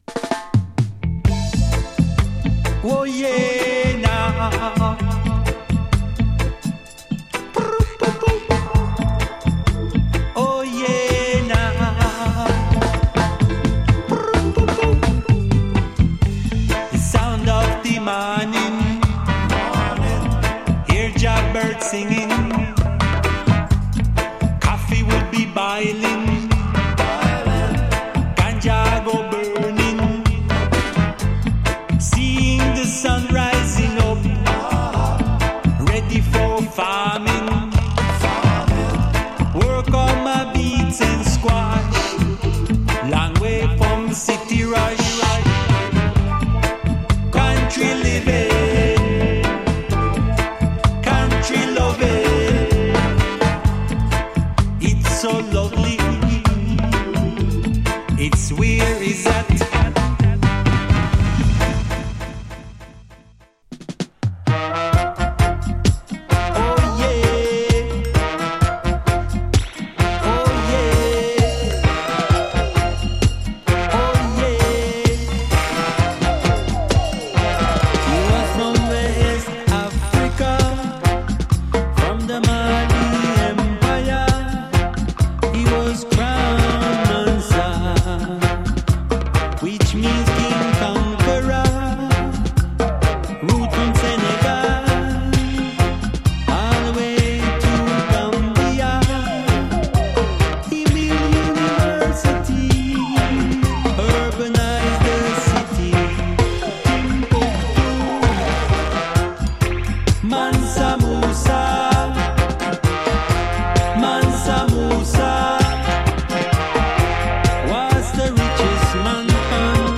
UK Roots / Modern Roots
VOCAL LP